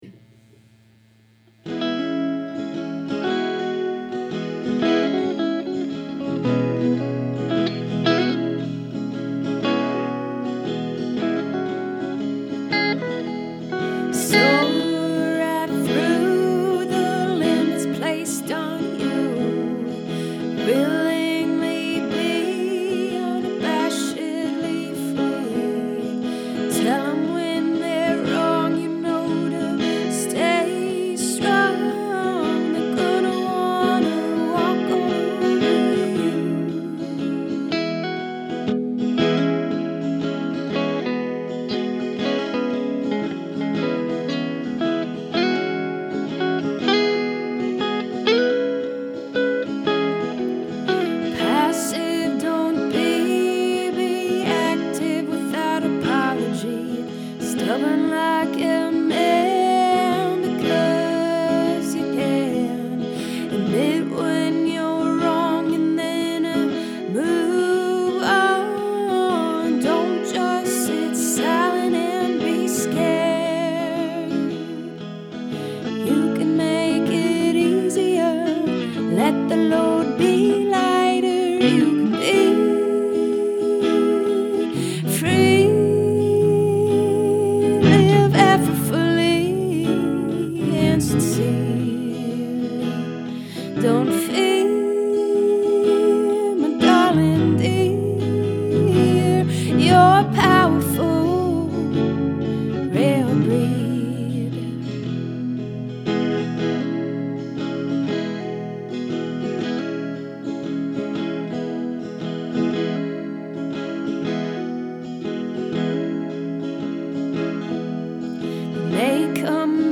Jessica Lea Mayfield-ish, w/ chorus guitar, good vox control